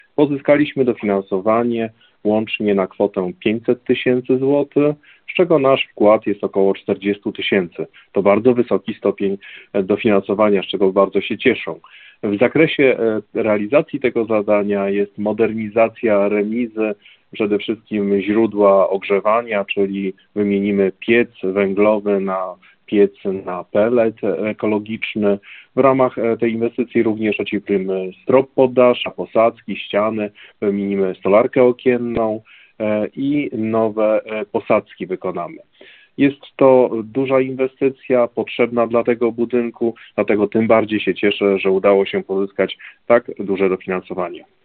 Mówił Marek Jasudowicz, wójt gminy Giżycko.